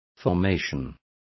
Complete with pronunciation of the translation of formations.